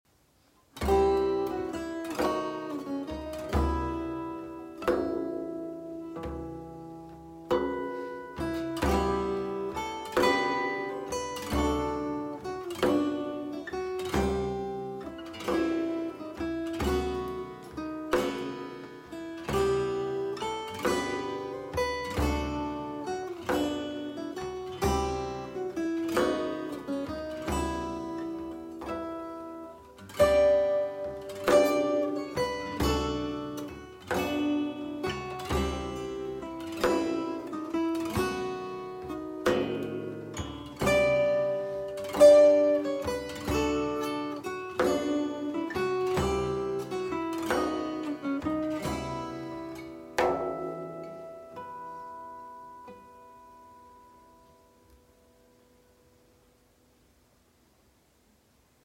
cantiques chrétiens
» Instrumental, à quatre voix…